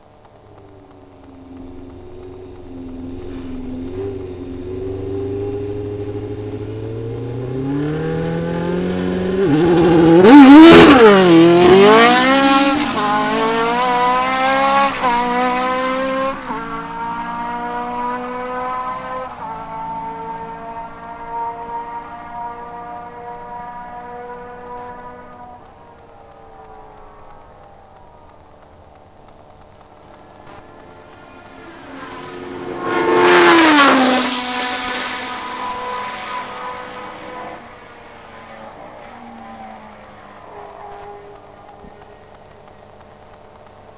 Stehender Start - Flat out - Vorbeifahrt Boxengasse
engine4.wav